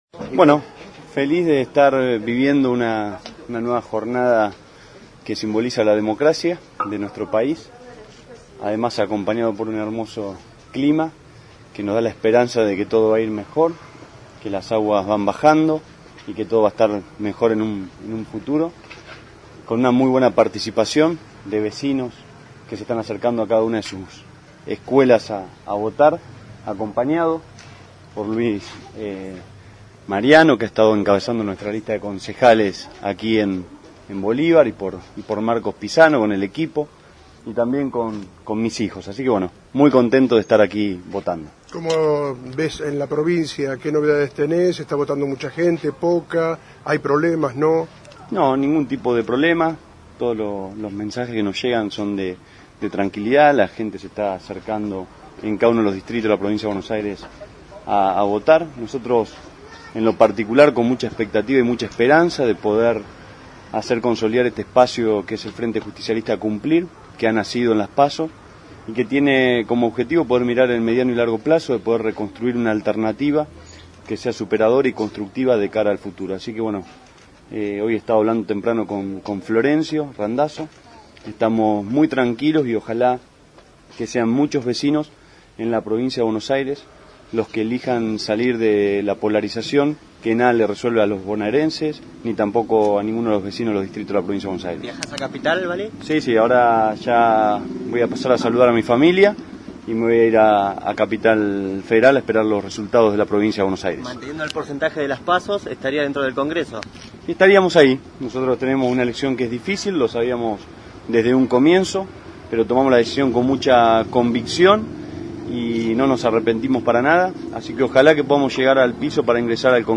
LA PALABRA DEL CANDIDATO A DIPUTADO NACIONAL POR CUMPLIR Y ACTUAL INTENDENTE DE BOLIVAR